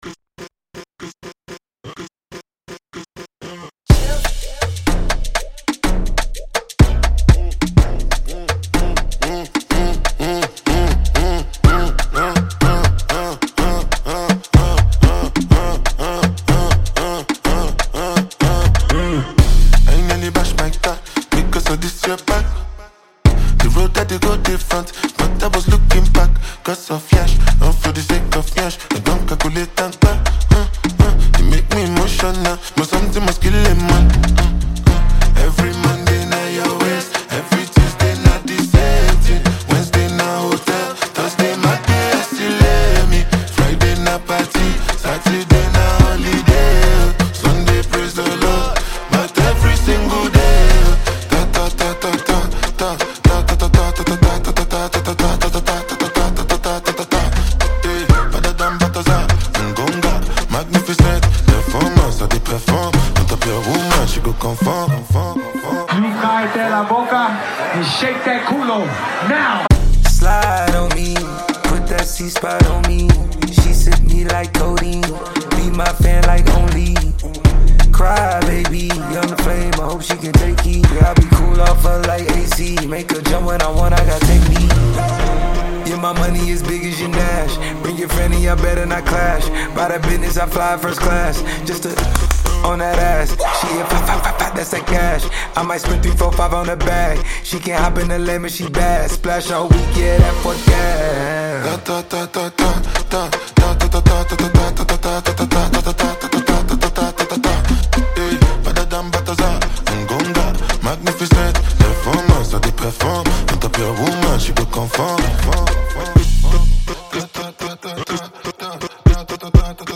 electrifying new track